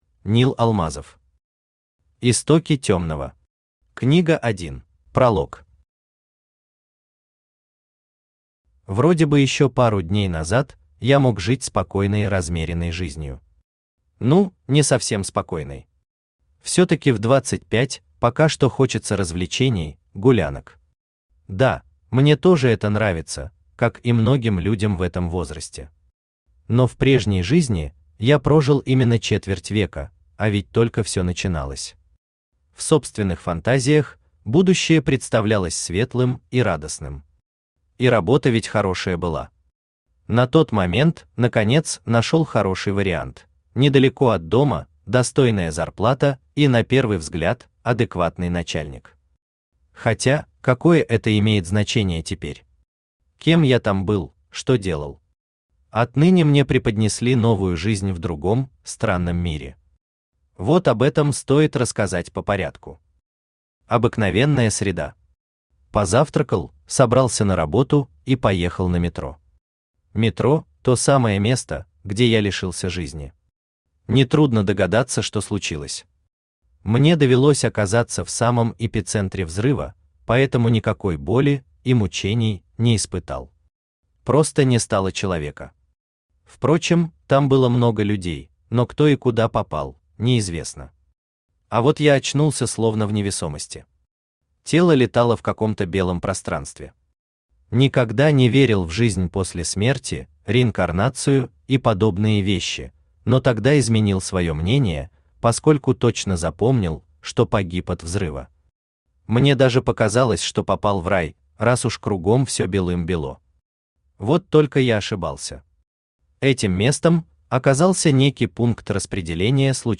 Аудиокнига Истоки Тёмного. Книга 1 | Библиотека аудиокниг
Aудиокнига Истоки Тёмного. Книга 1 Автор Нил Алмазов Читает аудиокнигу Авточтец ЛитРес.